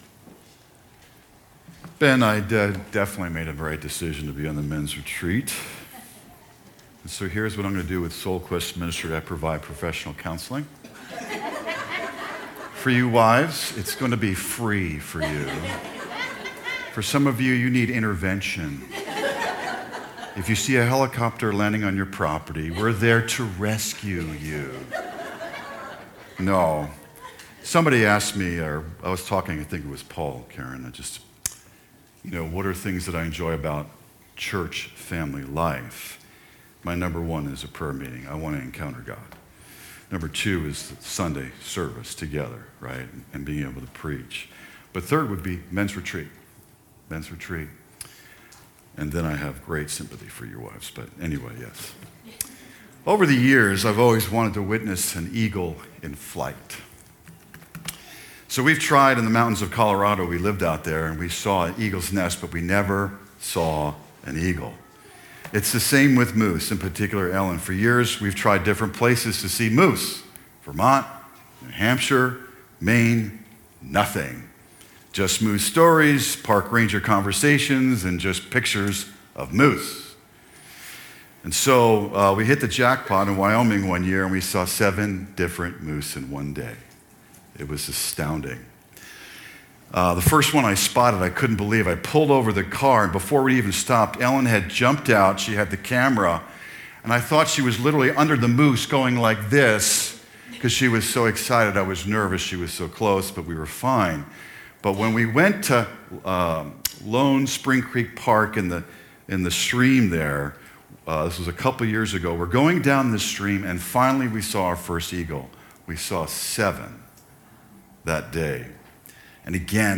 Sermons | New Buffalo Alliance Church